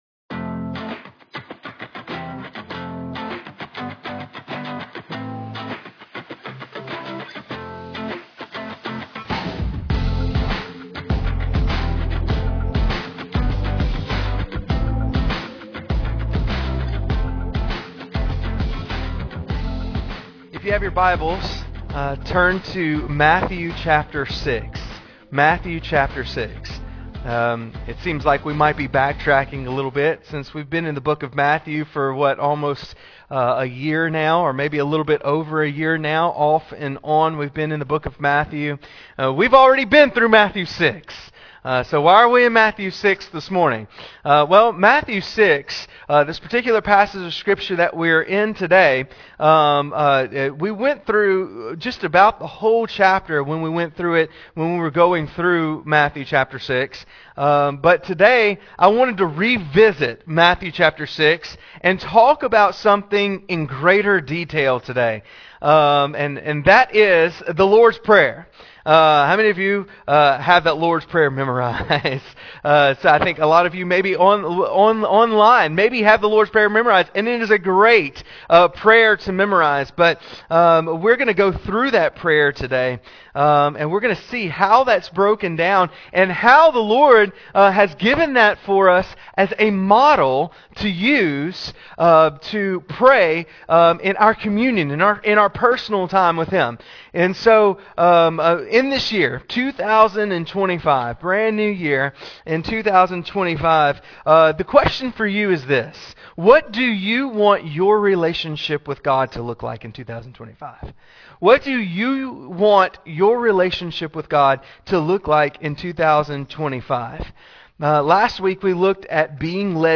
Sermons | Trace Creek Baptist Church